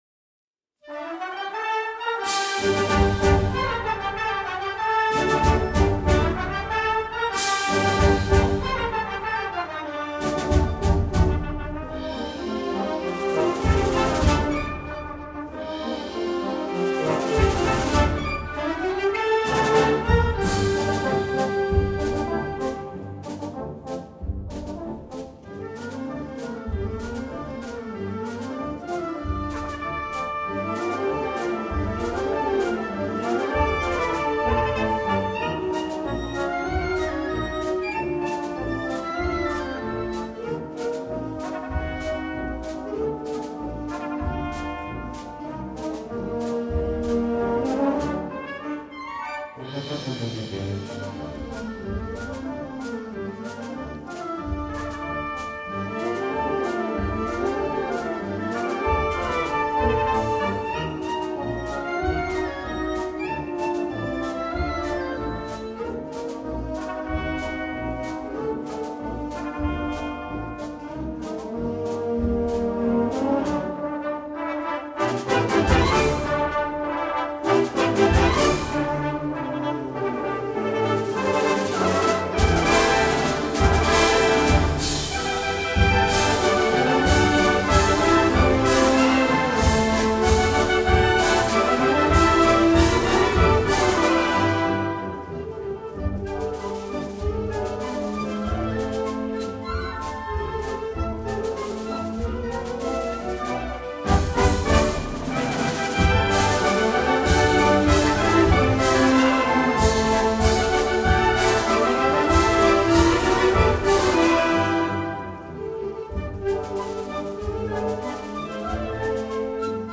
pasodoble
Gender: Rhapsody